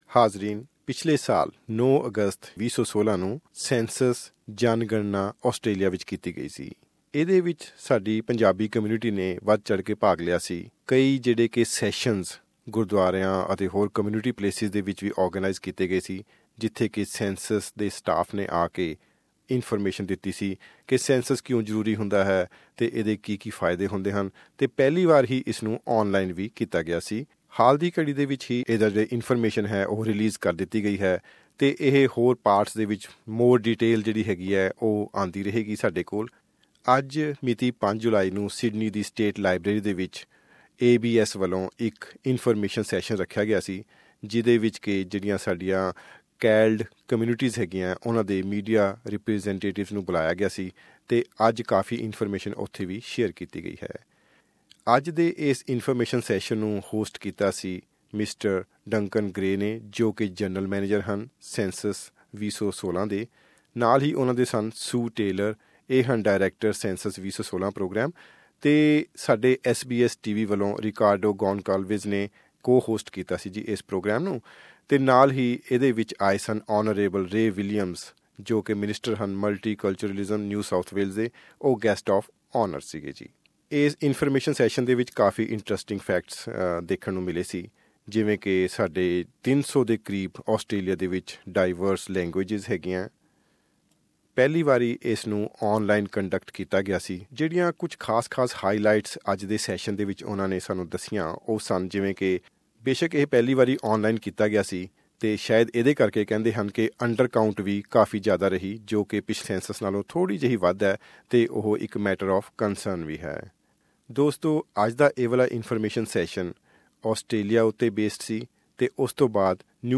A Census briefing was held in Sydney today (Wednesday, July 5) and Hon Ray Williams, Minister for Multiculturalism spoke to SBS Punjabi on the significance of the recently released Census data.
Hear our exclusive interview with Minster Ray Williams